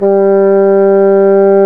Index of /90_sSampleCDs/Roland L-CDX-03 Disk 1/WND_Bassoons/WND_Bassoon 2
WND BASSOO0B.wav